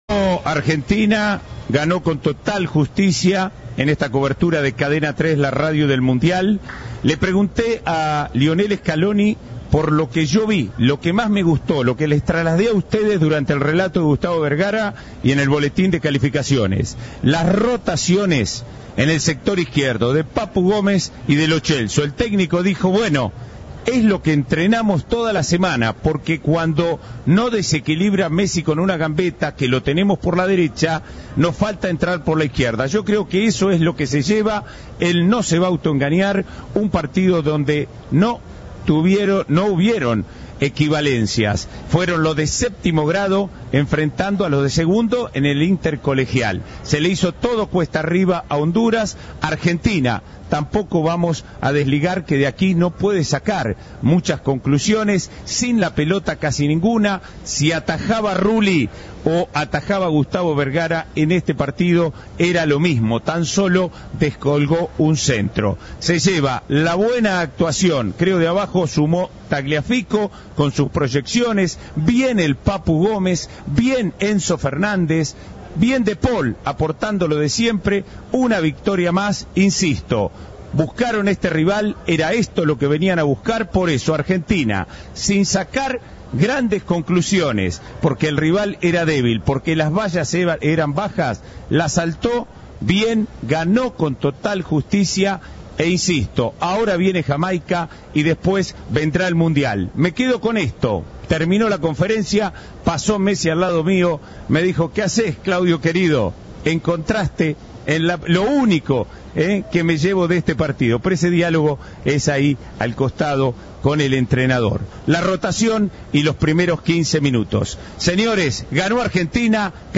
Comentario del partido